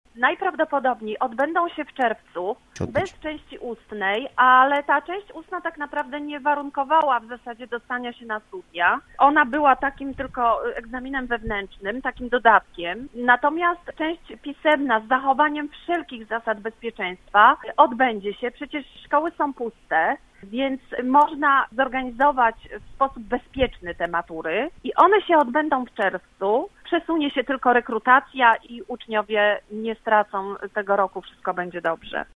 Odbyły się już klasyfikacyjne rady pedagogiczne i lada moment uczniowie dostaną świadectwa – mówiła lubuska wicekurator oświaty, która była gościem Rozmowy Punkt 9: